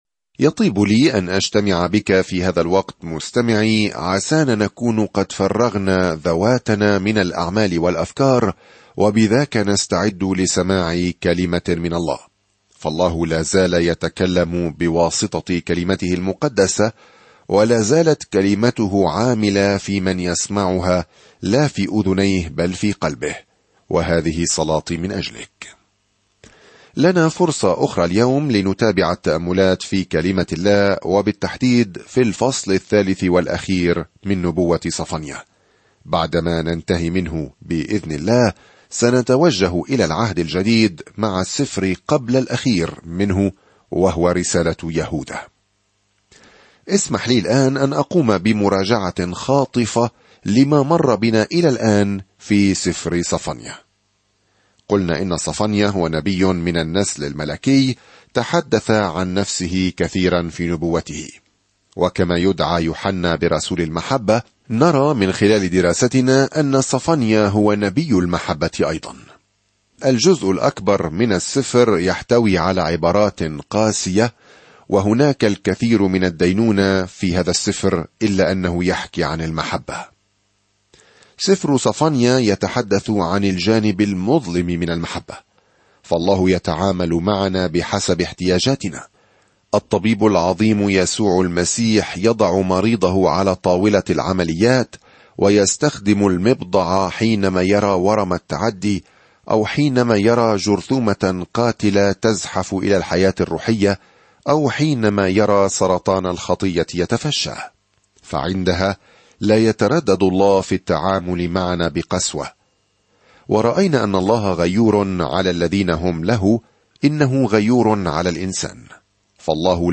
الكلمة صَفَنْيَا 3:3-8 يوم 5 ابدأ هذه الخطة يوم 7 عن هذه الخطة يحذر صفنيا إسرائيل من أن الله سيدينهم، لكنه يخبرهم أيضًا كم يحبهم وكيف سيبتهج بهم يومًا ما بالغناء. سافر يوميًا عبر صفنيا وأنت تستمع إلى الدراسة الصوتية وتقرأ آيات مختارة من كلمة الله.